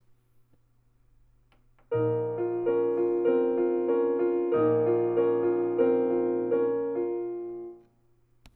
Its sound is much richer and more resonant than my old piano, and there are more choices and features, which will be fun to explore in the coming days.
Literally four beats repeated once.